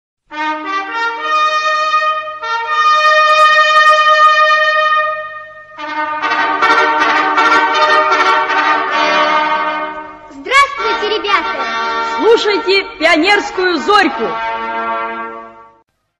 Звук заставки